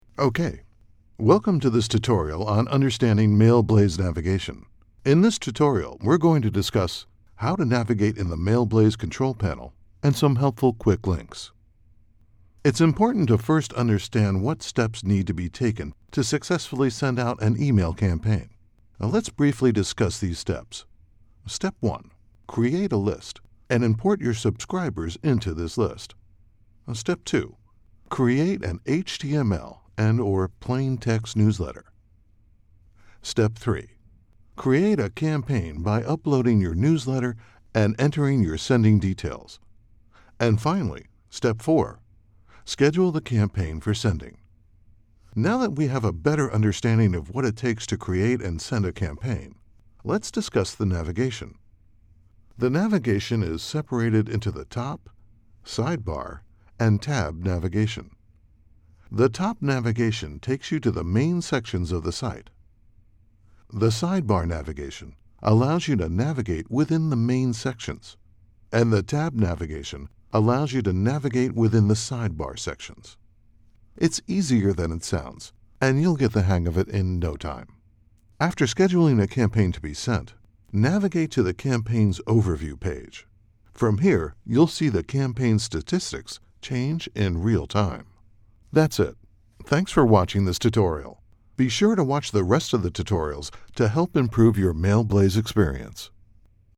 Confident, sophisticated, strong, commanding, conversational, sexy, cool, wry, serious or tongue and cheek.
Sprechprobe: eLearning (Muttersprache):
My full service, State of the Art studio is based in midtown Manhattan, your final recording will be produced at broadcast quality.